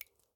drip_water12.ogg - 25w18a
drip_water12.ogg